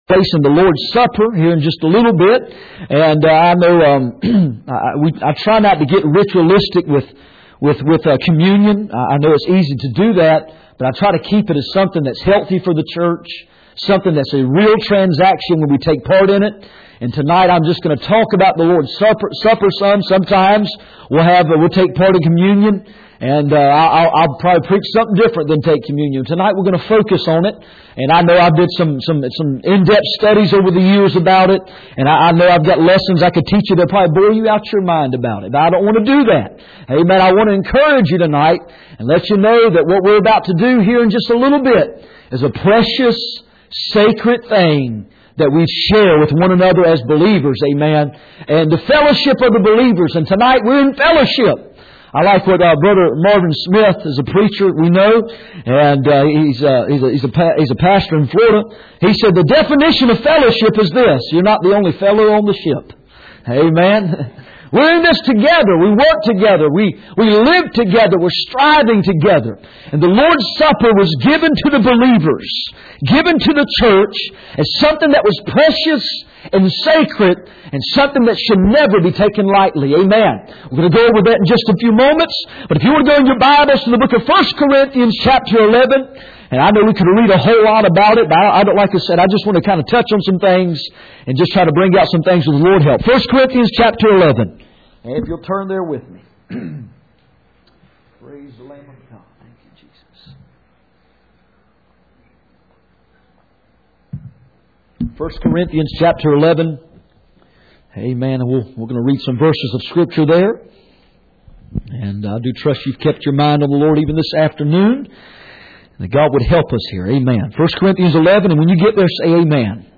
Passage: 1 Corinthians 11:23-34 Service Type: Sunday Evening